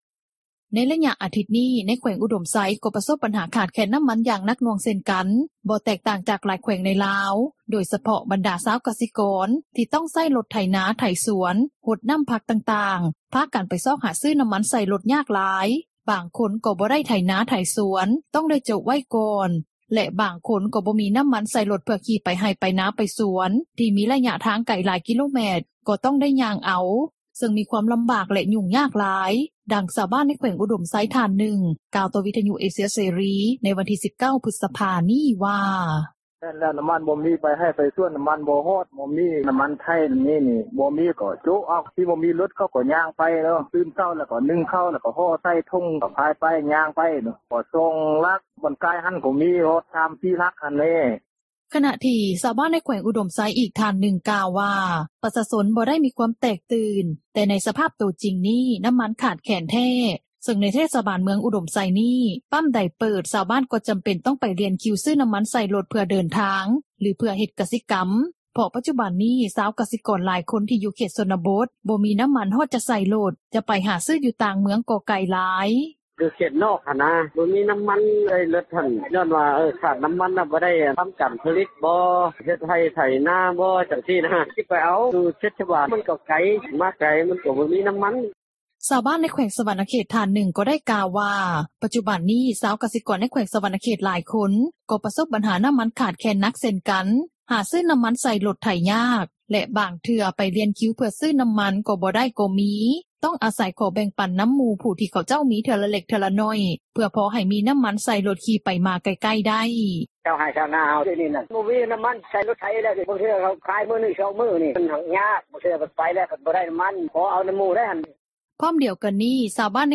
ດັ່ງຊາວບ້ານ ໃນແຂວງອຸດົມໄຊ ທ່ານນຶ່ງ ກ່່າວຕໍ່ວິທຍຸເອເຊັຽເສຣີ ໃນວັນທີ່ 19 ພຶສພາ ນີ້ວ່າ: